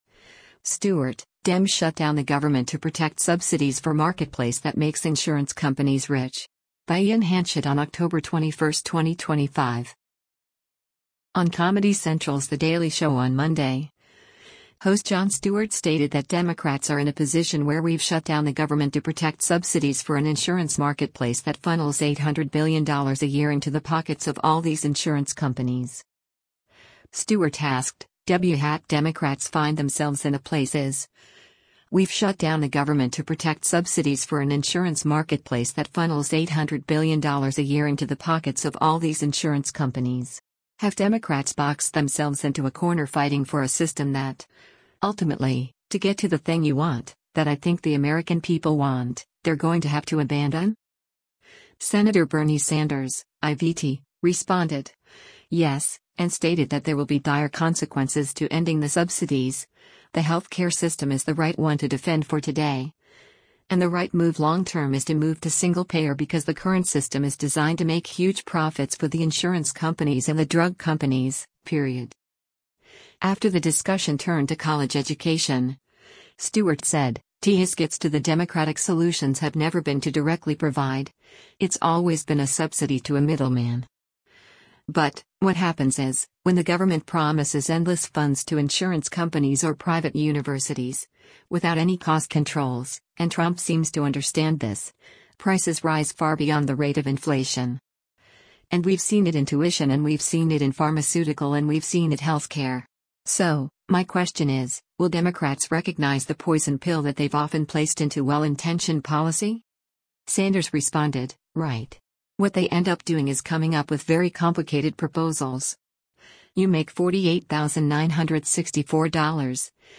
On Comedy Central’s “The Daily Show” on Monday, host Jon Stewart stated that Democrats are in a position where “we’ve shut down the government to protect subsidies for an insurance marketplace that funnels $800 billion a year into the pockets of all these insurance companies.”